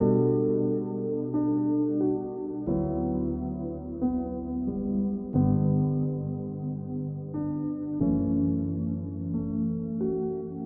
悲伤的和弦
描述：D大调悲哀的陷阱，N的罗非
Tag: 90 bpm Hip Hop Loops Piano Loops 1.79 MB wav Key : D